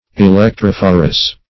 Electrophorus \E*lec`troph"o*rus\, n.; pl. Electrophori. [NL.,